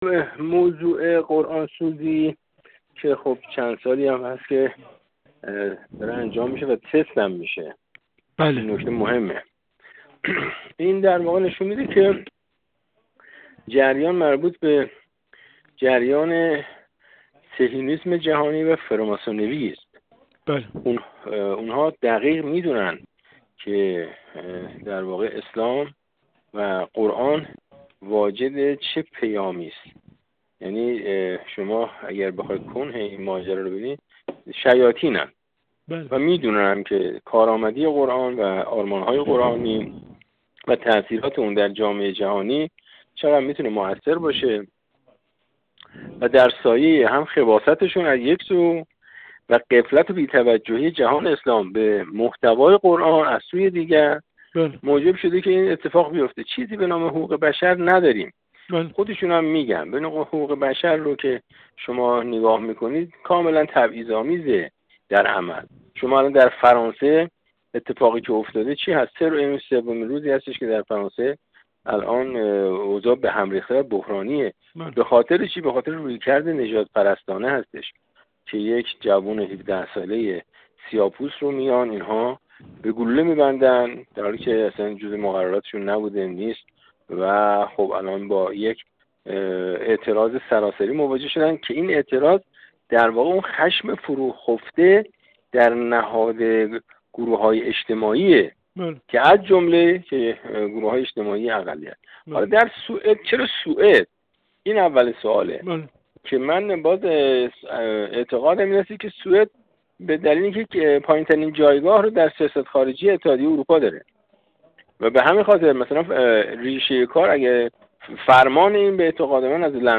ابوالفضل ظهره‌وند، سفیر پیشین ایران در افغانستان و ایتالیا
گفت‌وگو